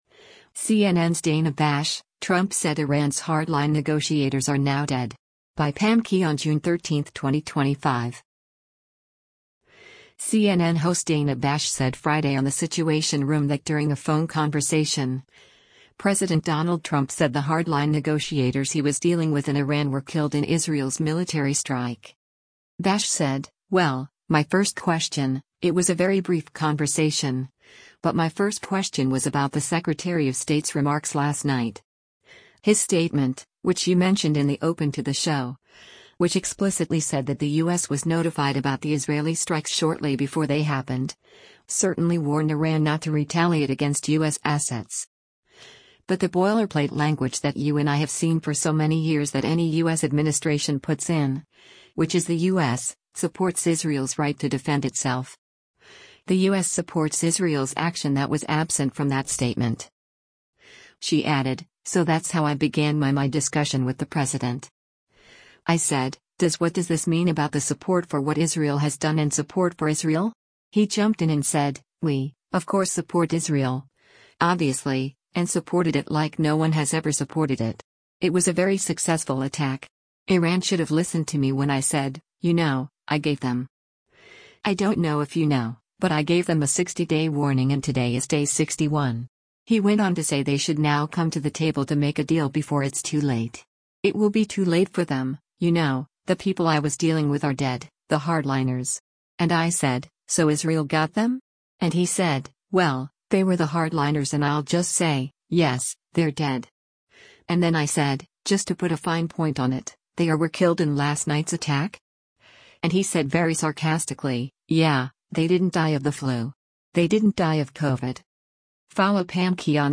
CNN host Dana Bash said Friday on “The Situation Room” that during a phone conversation, President Donald Trump said the hardline negotiators he was dealing with in Iran were killed in Israel’s military strike.